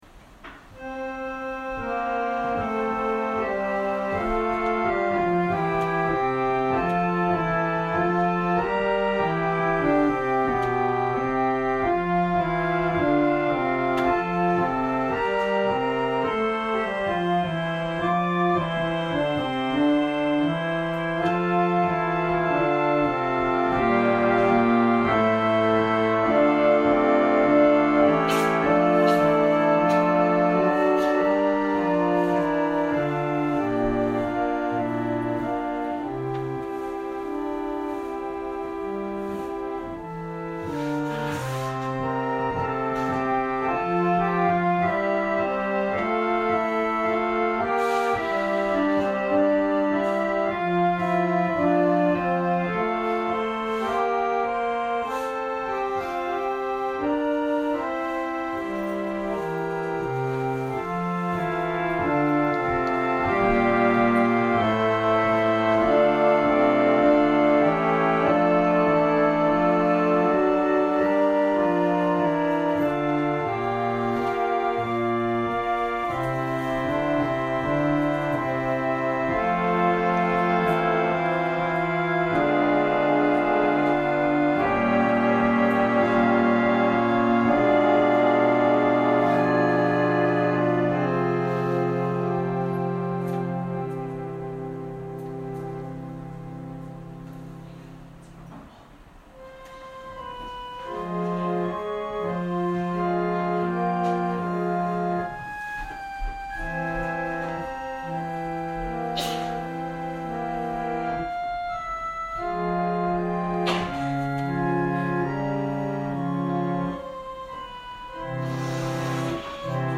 千間台教会。説教アーカイブ。
私たちは毎週日曜日10時20分から12時まで神様に祈りと感謝をささげる礼拝を開いています。